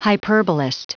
Prononciation du mot hyperbolist en anglais (fichier audio)
Prononciation du mot : hyperbolist